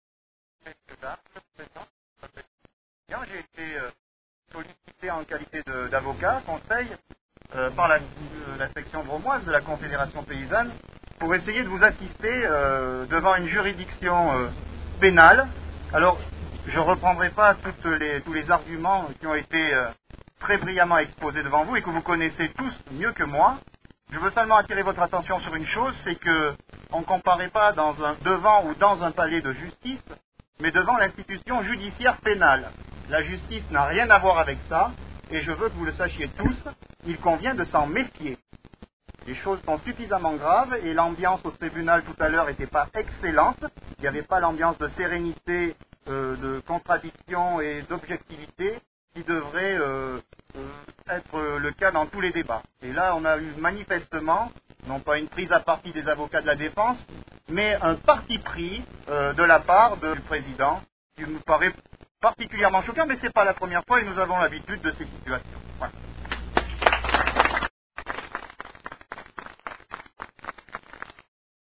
Les Interviews de Radio-Méga
A la sortie du tribunal: